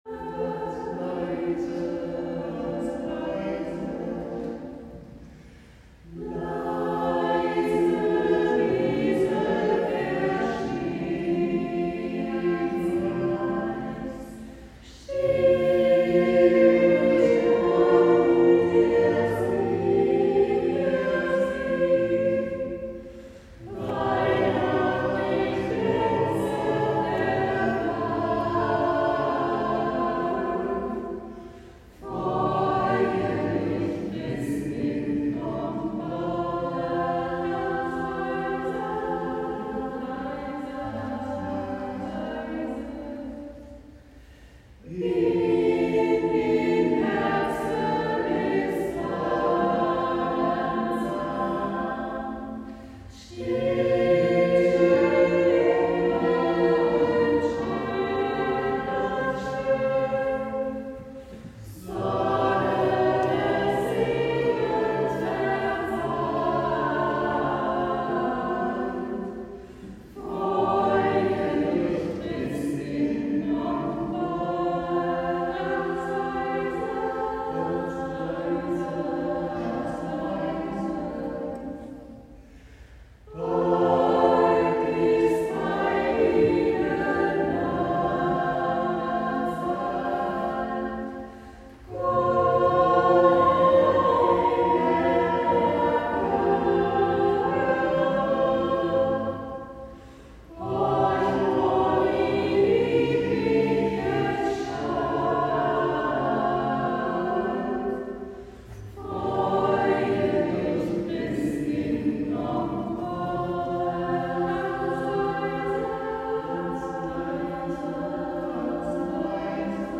Am gestrigen Sonntag, 29. Dez. waren wieder viele Besucher zum „Offenen Singen zur Weihnachtszeit“ in die Peckelsheimer Pfarrkirche gekommen.
Besonders beeindruckend war dabei eine A cappella Version des bekannten Volksliedes „Leise rieselt der Schnee„.
PH24_Offenes-Singen-Kantoren.m4a